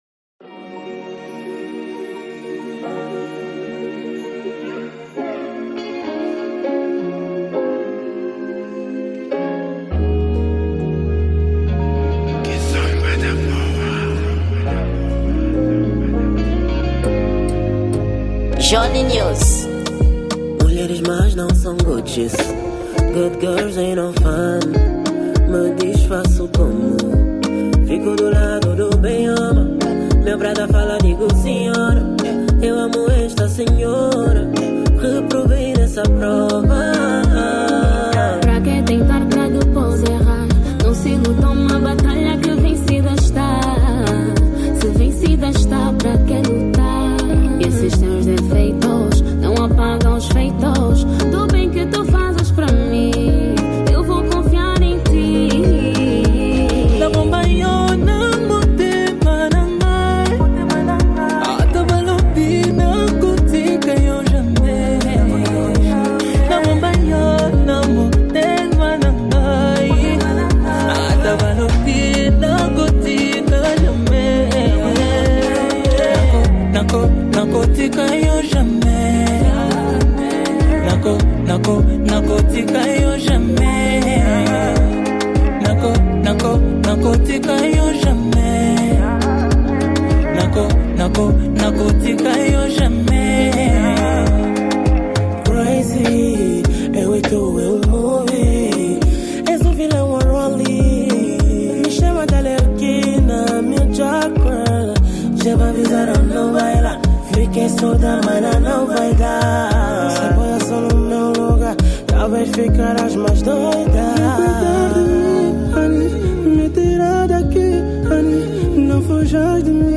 Gênero: Kizomba